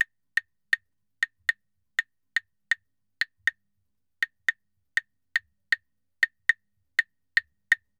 Claves_Salsa 120_1.wav